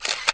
camera_shutter_1.wav